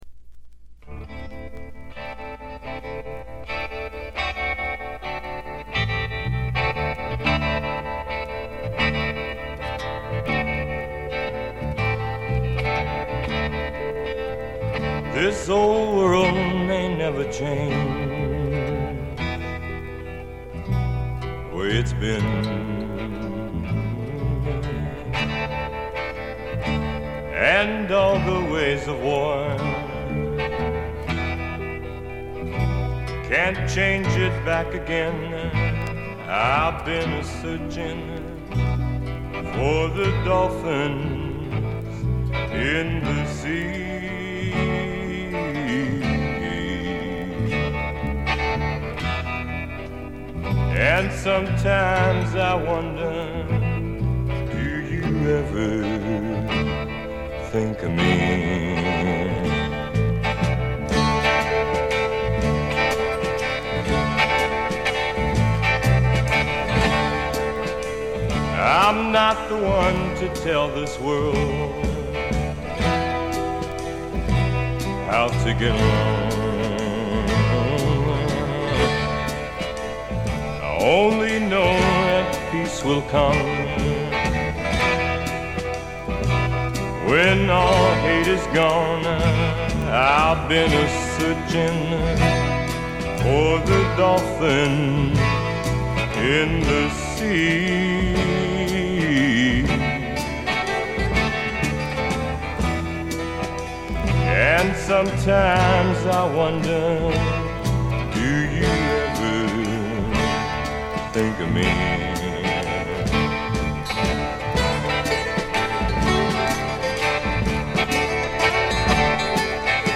ごくわずかなノイズ感のみ。
言わずとしれた60年代を代表するフォーク／アシッド・フォークの大名作ですね。
地を這うように流れ出すヴォーカルには底なし沼の深淵に引きずり込まれるような恐怖とぞくぞくする快感を覚えます。
モノラル盤。
試聴曲は現品からの取り込み音源です。